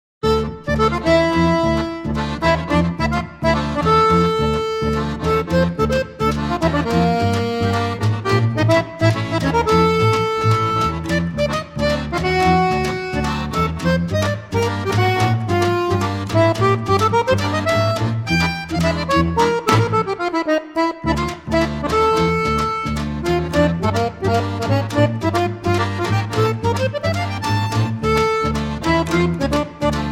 written for two accordions as well as accordion ensemble
The album is melodic as melody is the root of the story.
string bass
bass accordion
drums